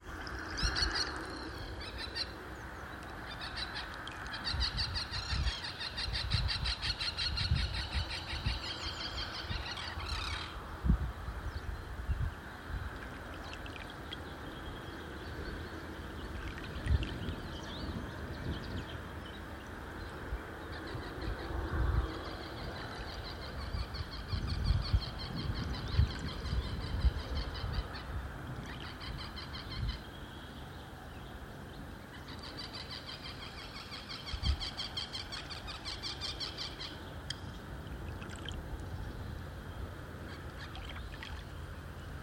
Birds -> Terns ->
Common Tern, Sterna hirundo